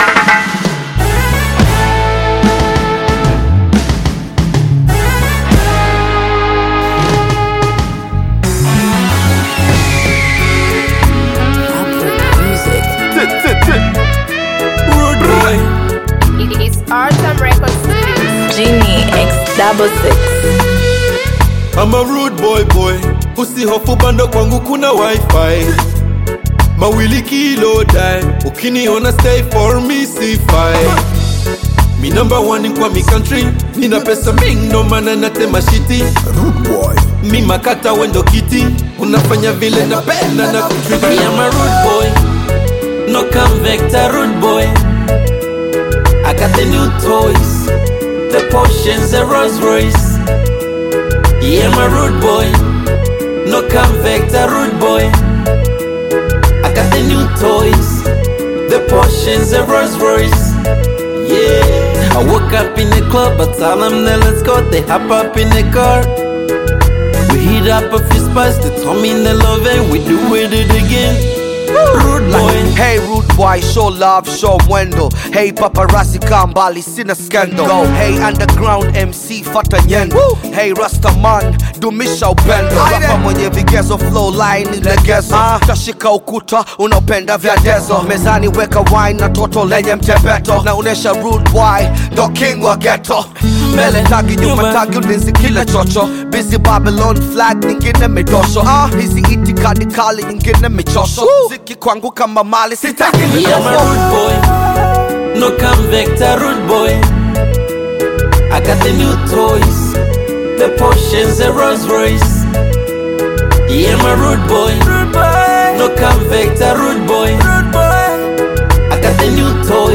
African Music